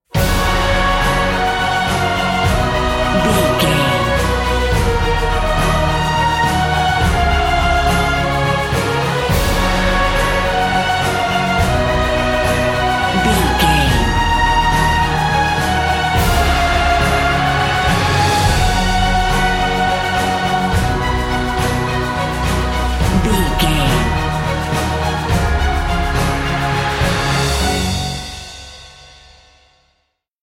Uplifting
Ionian/Major
F♯
orchestral
brass
percussion
strings